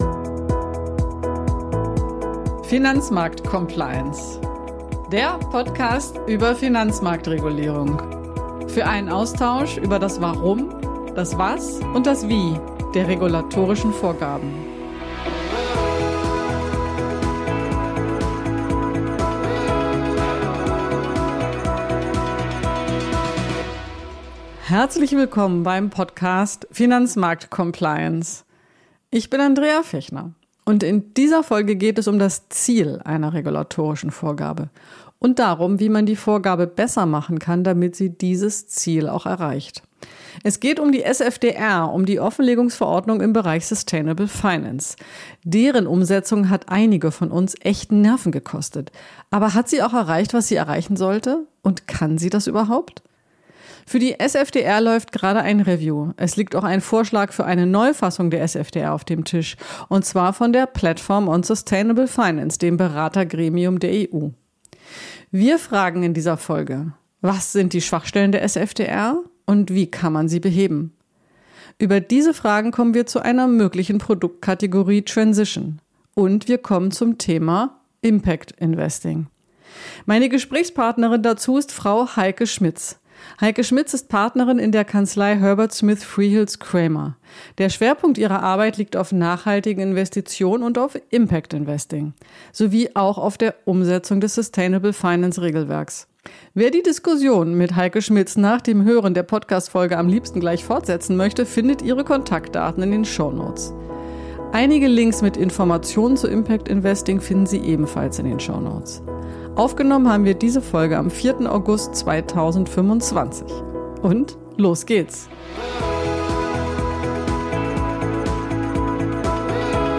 Gesprächspartnerin